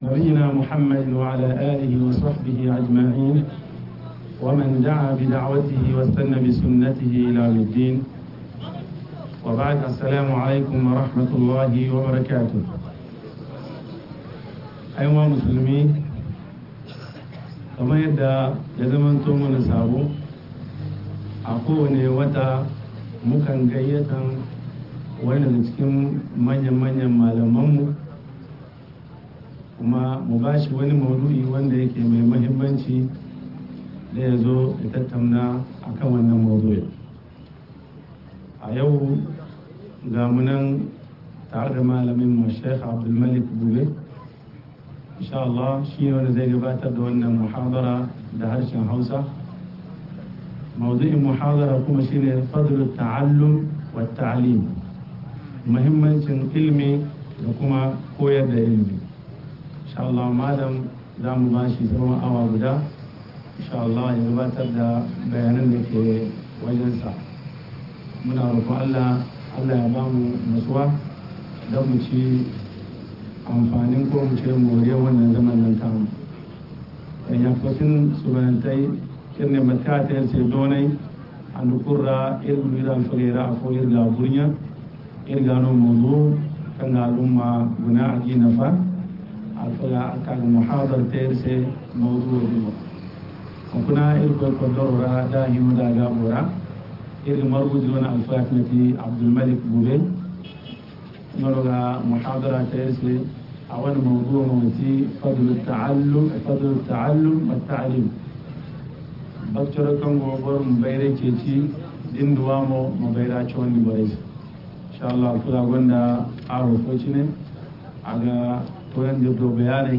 Muhimmancin ilimi a rayuwa - MUHADARA